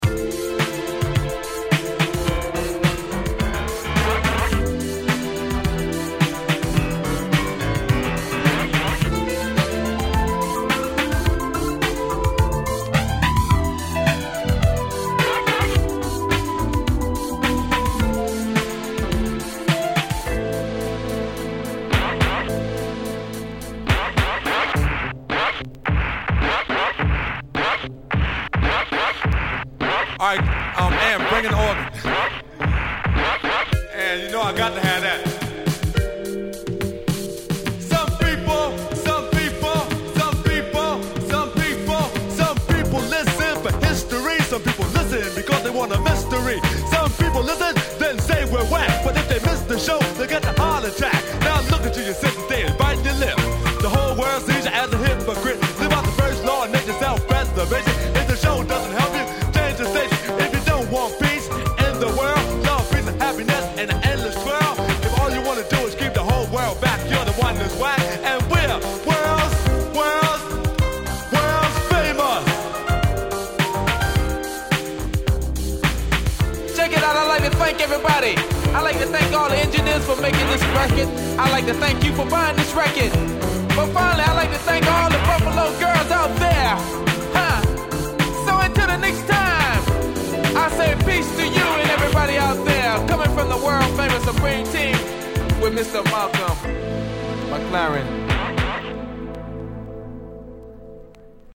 83' Super Old School Classic !!
Pianoの音色が気持ち良い鉄板クラシック